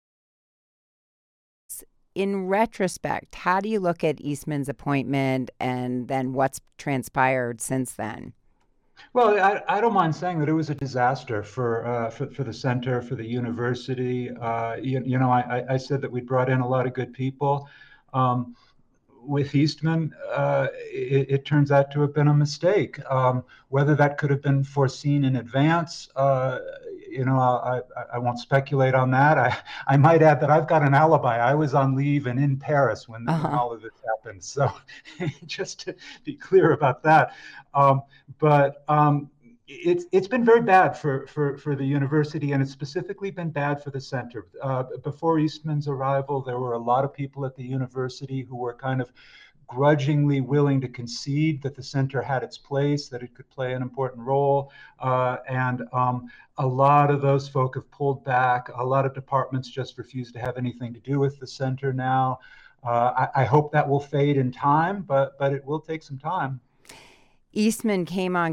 On Wednesday, Colorado Public Radio aired an interview